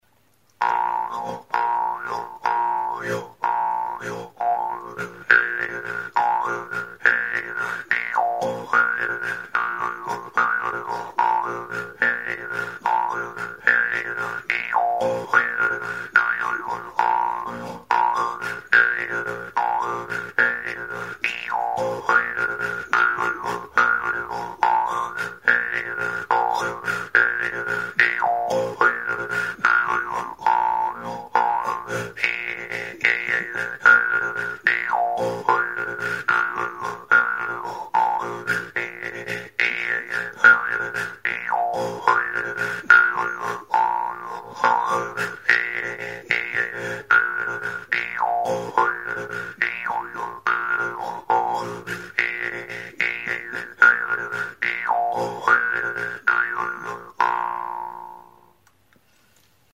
Idiófonos -> Punteados / flexible -> Sin caja de resonancia
Grabado con este instrumento.
Altzairuzko mihi luzea du erdi-erdian, hatzarekin astintzerakoan libre bibratzen duena.